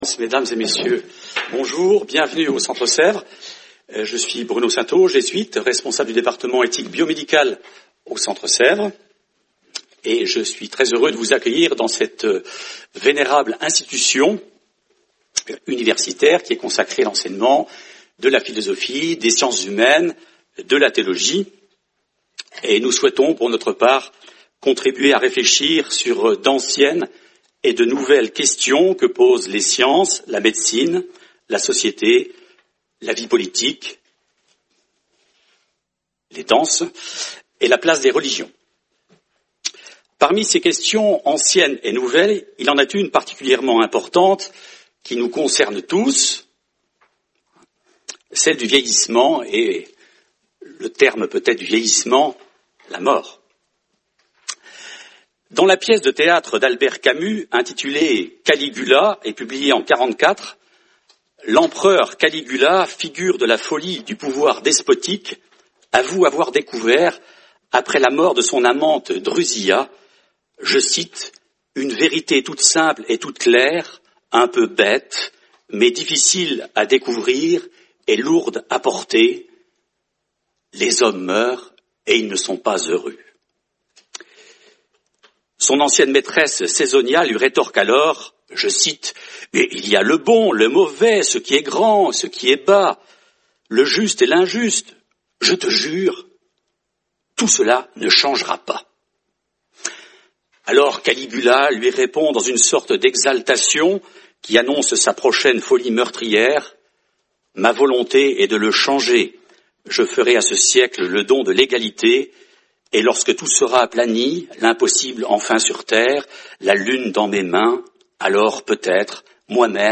Colloque du 12 janvier 2019, organisé par le département d’éthique biomédicale du Centre Sèvres.